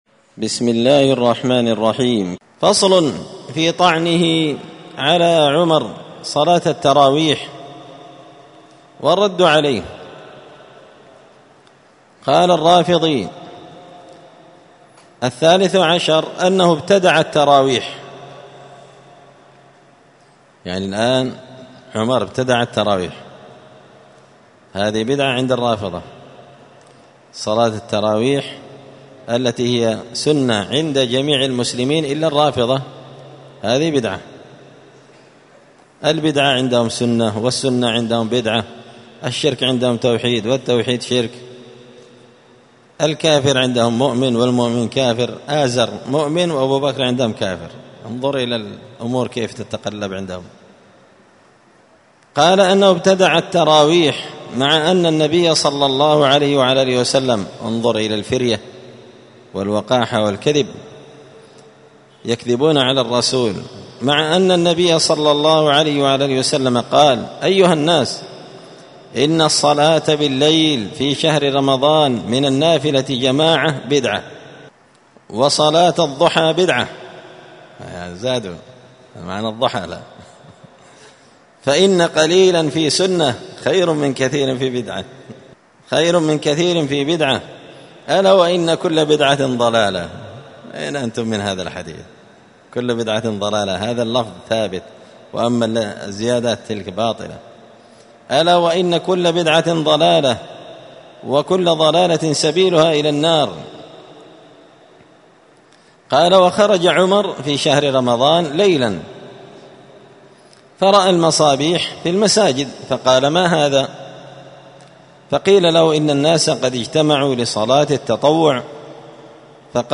*الدرس الخامس والثلاثون بعد المائتين (235) فصل في طعن الرافضي على عمر صلاة التراويح والرد عليه*